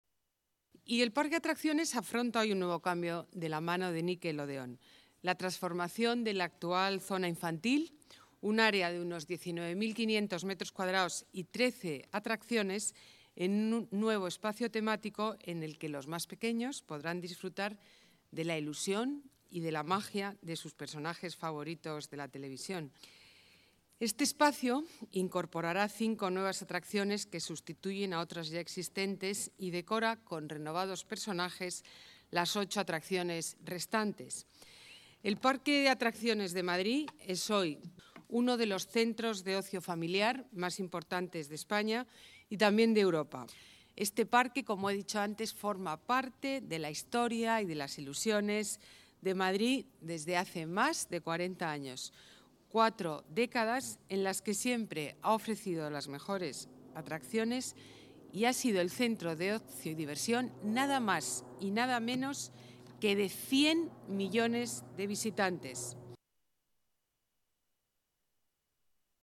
La alcaldesa, Ana Botella, asiste a la colocación de la primera piedra de Nickelodeonland, en la que se van a invertir cinco millones de euros
Nueva ventana:Declaraciones de la alcaldesa, Ana Botella